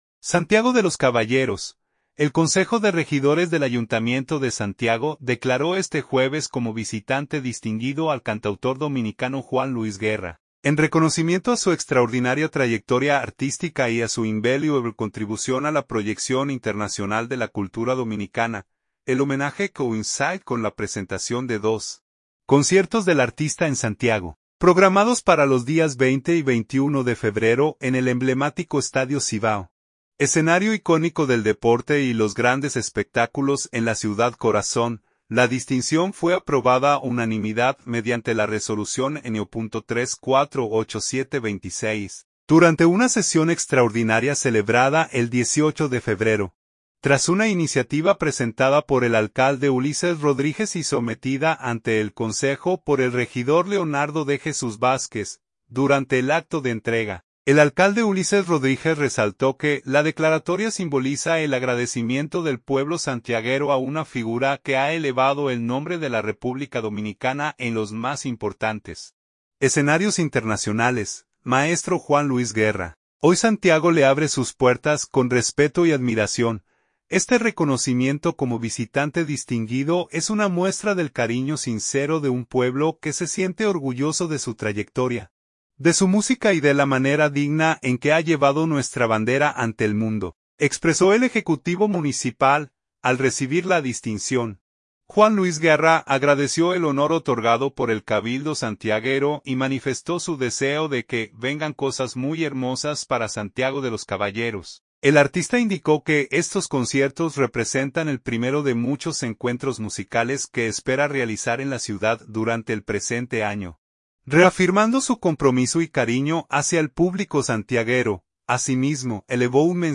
El acto de reconocimiento fue realizado detrás de la tarima instalada para los conciertos en el Estadio Cibao.